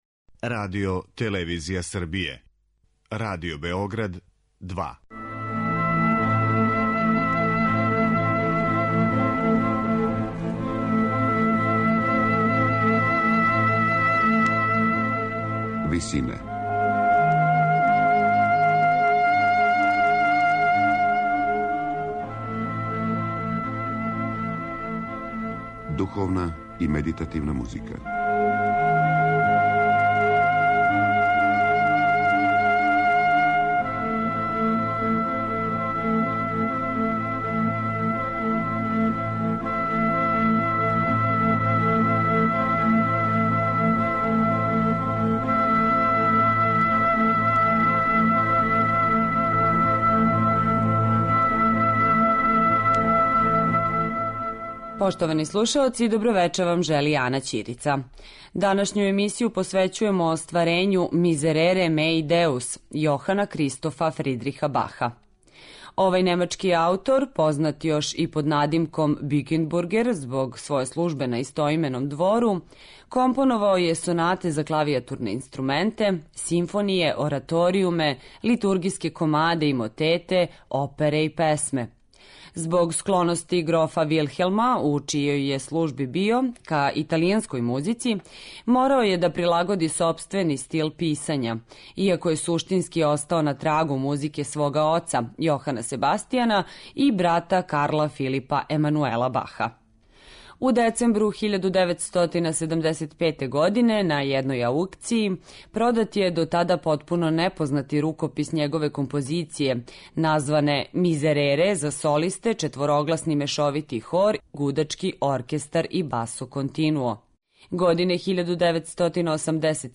Духовна и медитативна музика